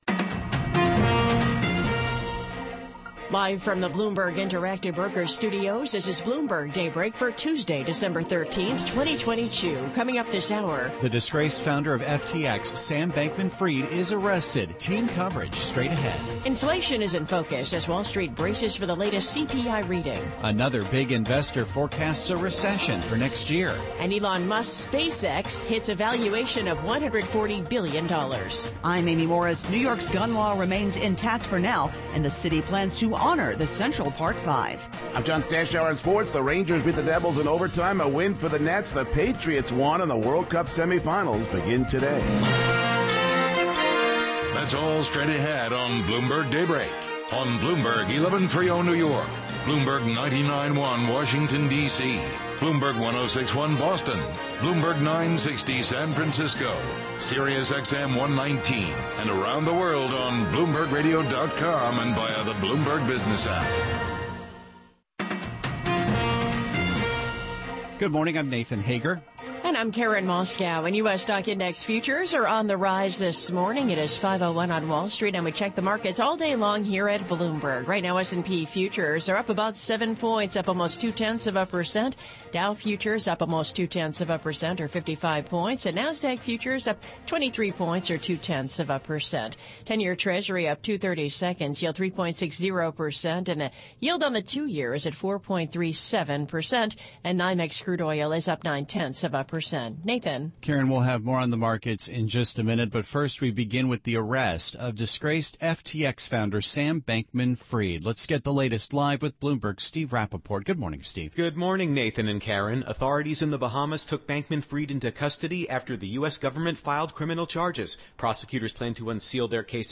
Bloomberg Daybreak: December 13, 2022 - Hour 1 (Radio)